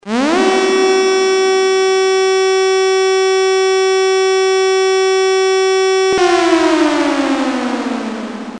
Trois signaux sonores prolongés et modulés (montant et descendant), d’une minute et quarante et une secondes chacun, séparés d’un intervalle de cinq secondes.
La sirène d’alerte «accident radioactif».